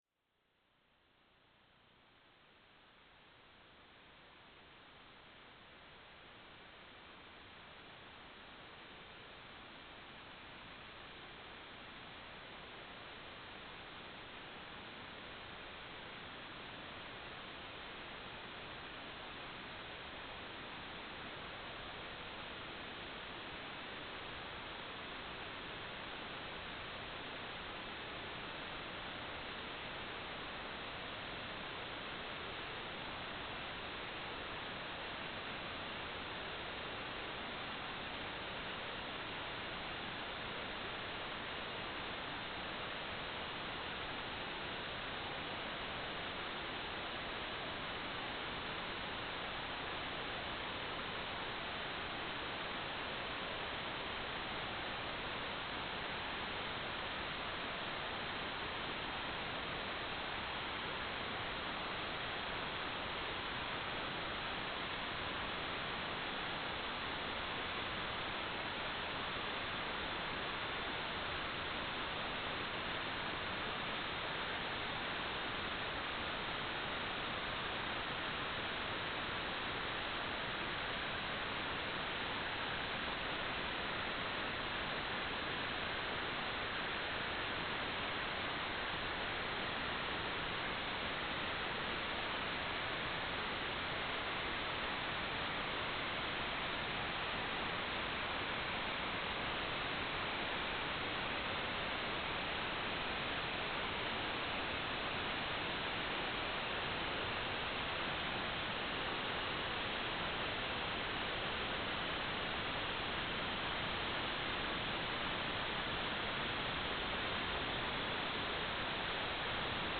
"waterfall_status": "without-signal",